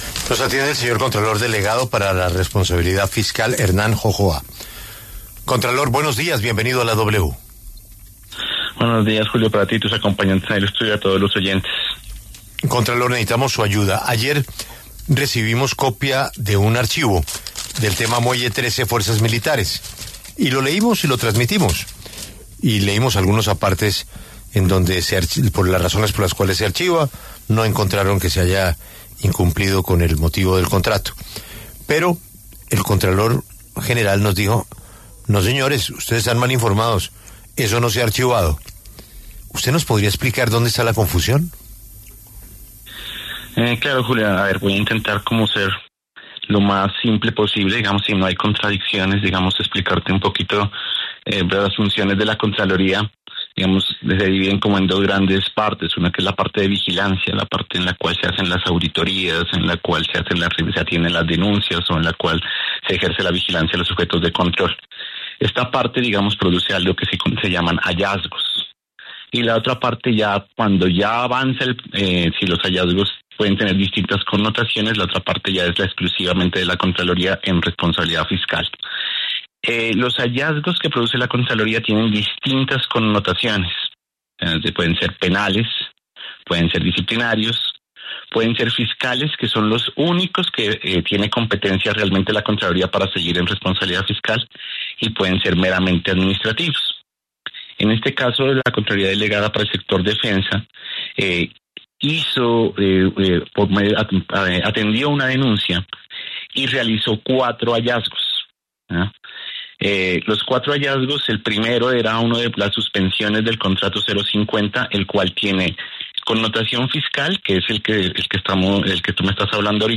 El contralor delegado para la responsabilidad fiscal, Hernán Jojoa, explicó en W Radio que los motivos por los cuales en varias ocasiones se suspendió la operación del puerto están debidamente acreditadas y por eso no hubo daño al patrimonio por $3.000 millones.